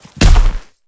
Sfx_creature_snowstalker_run_os_07.ogg